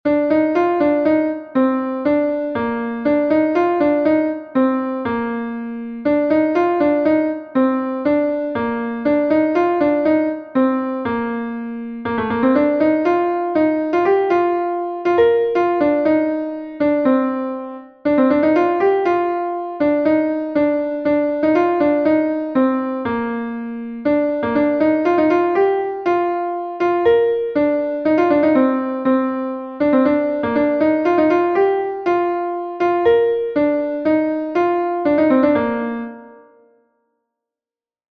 Ton Bale Plozeved is a Bale from Brittany